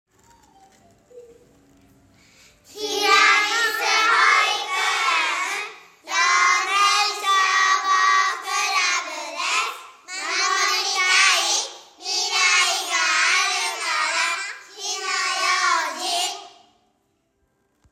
「火災予防運動」や「歳末警戒」の一環で、管内の子ども達（幼年消防クラブ員）が録音した音声を消防車から流し、パトロールをします。
子ども達は、かわいい元気いっぱいの声で「守りたい 未来があるから 火の用心」と音声を録音して協力して頂きました。
広報音声（順不同）